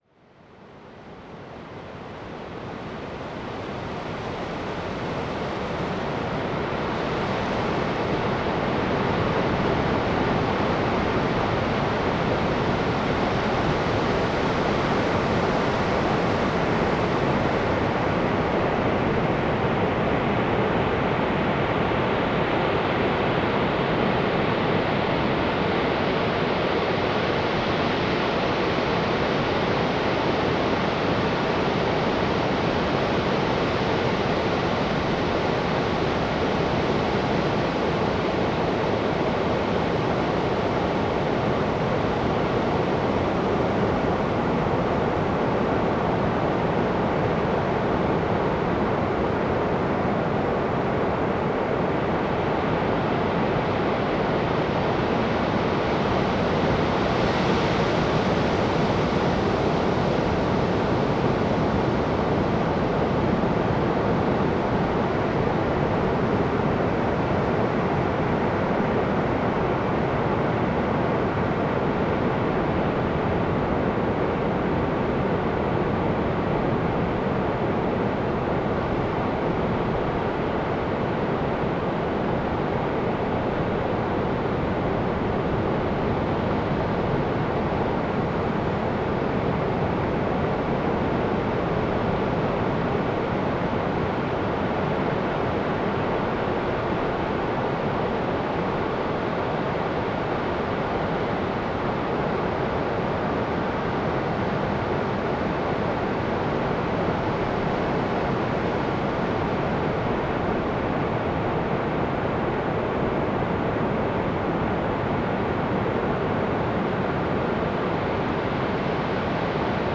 Cox Bay Surf
Spent a few days exploring the beach in November in both sunny and cloudy weather at Cox Bay on the West Coast of Vancouver Island.
While walking the beach I noticed that the sound would rise and fall rather noticeably, almost as if someone was playing with the volume control. The higher/brighter sound of the waves seems to be the water receding after stretching way up the sandy beach. When no waves made it up the beach for a spell the lower/deeper sound was the surf crashing in the deeper waters away from the beach and sand.